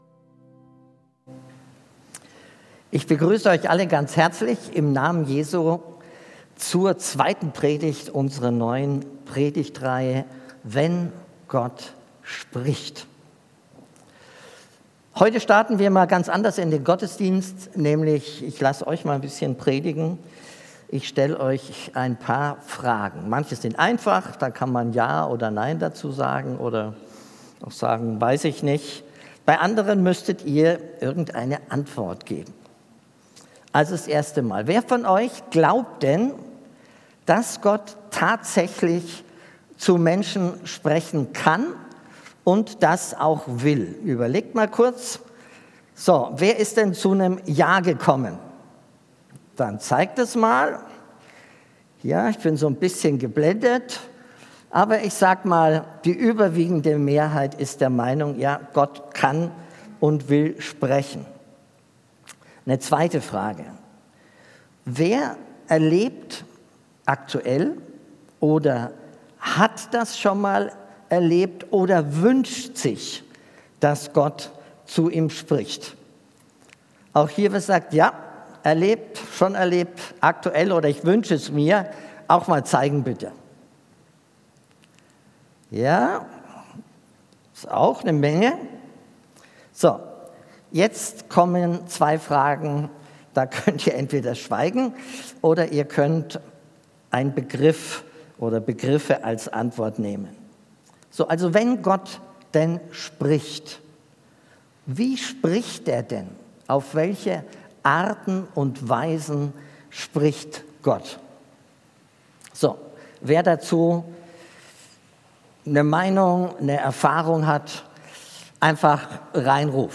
Predigt Wenn Gott spricht